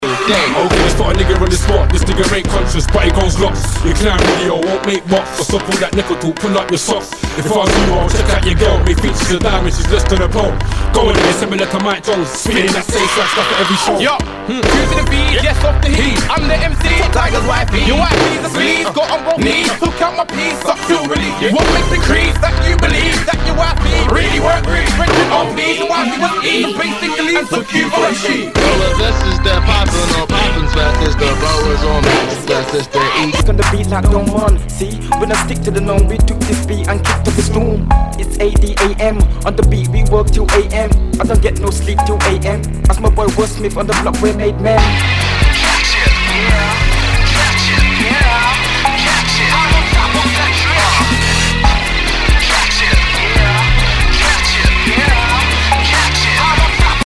Nu- Jazz/BREAK BEATS
ポストパンク・リミックス盤！